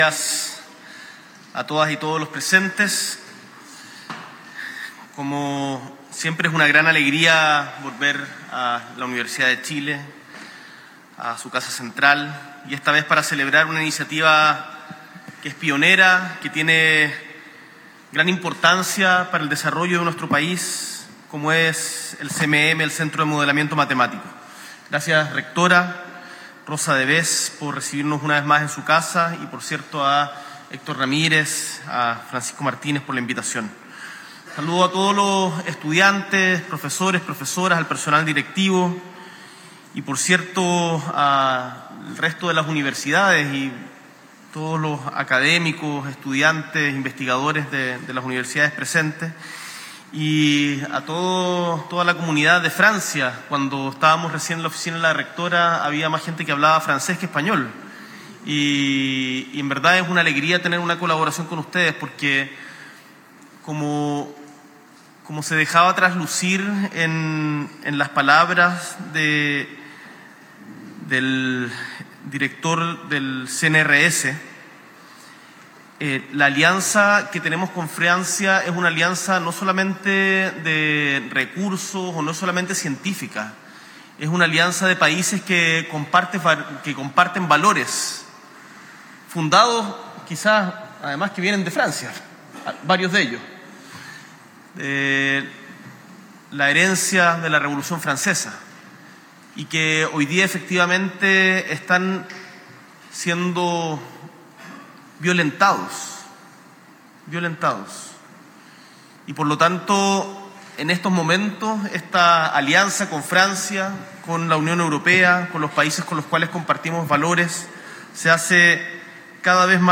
S.E. el Presidente de la República, Gabriel Boric Font, participa de la ceremonia de conmemoración de los 25 años del Centro de Modelamiento Matemático de la Universidad de Chile.
Discurso